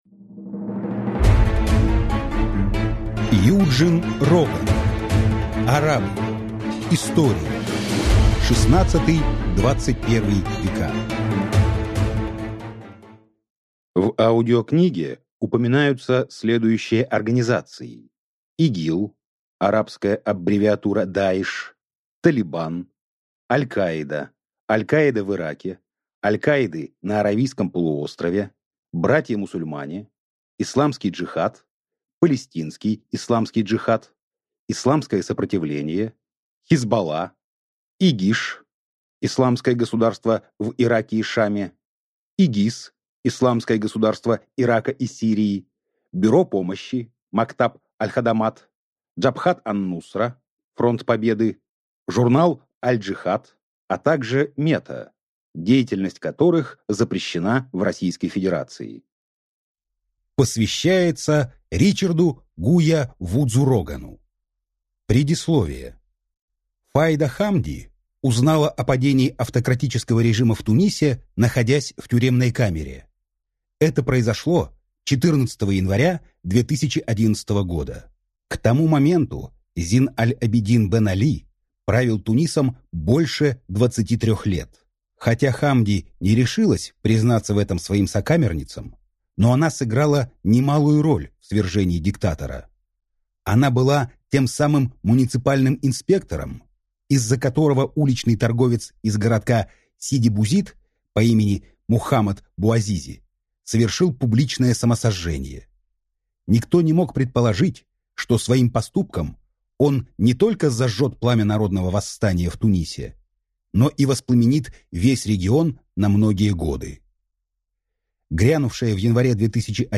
Аудиокнига Арабы. История. XVI–XXI вв.